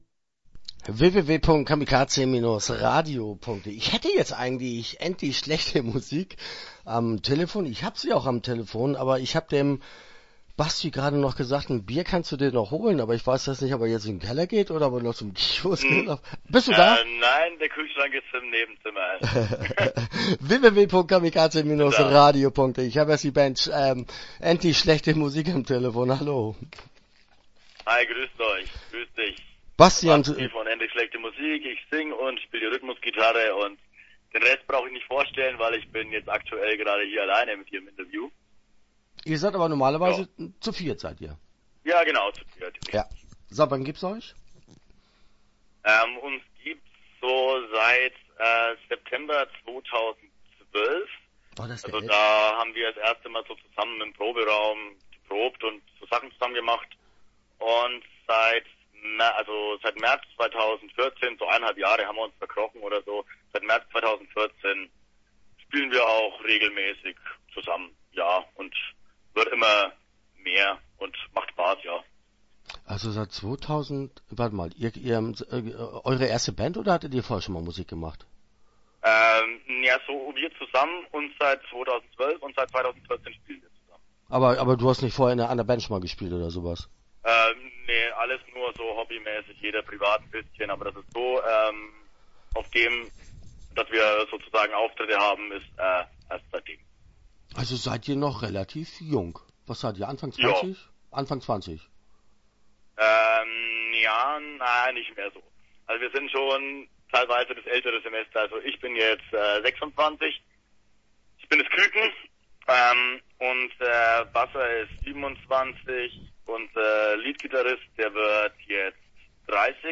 Endlich schlechte Musik - Interview Teil 1 (10:54)